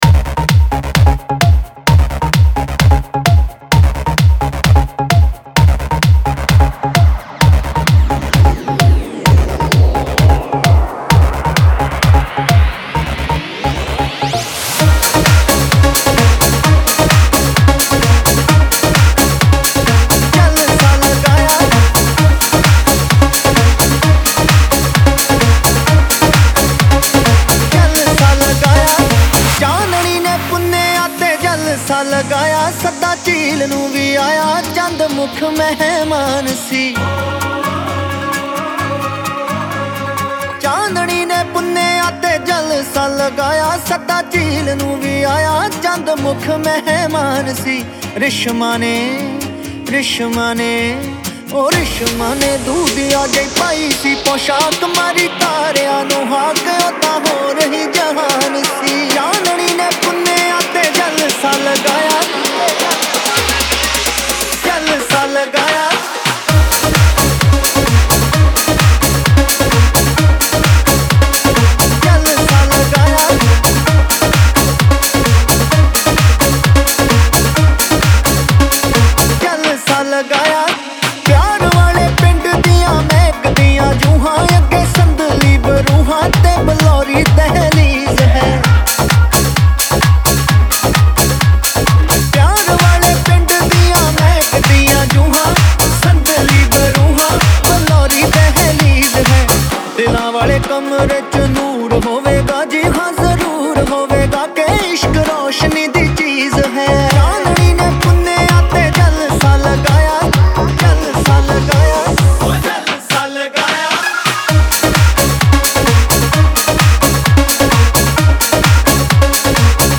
Wedding Mix